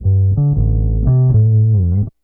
BASS 13.wav